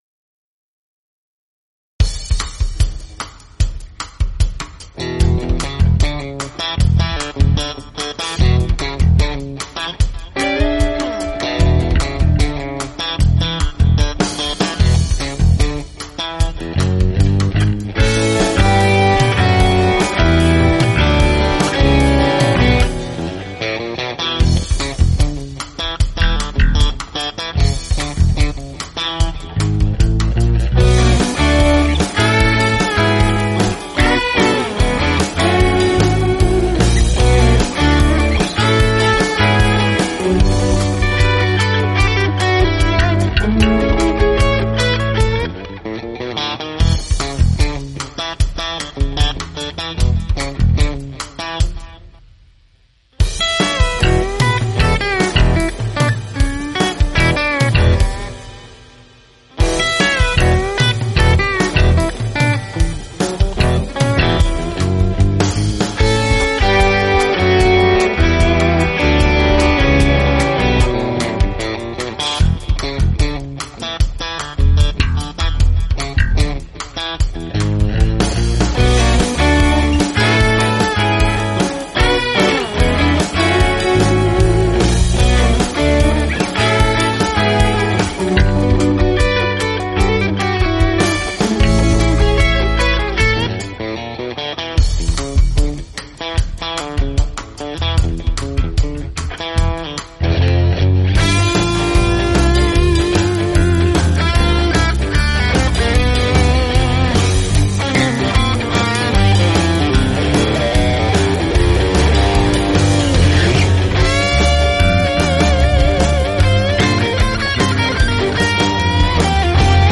Guitars, bass, and drum programming.
Recorded, mixed and mastered in Presonus Studio One 4.6 at home Studio in San Luis Obispo, Ca.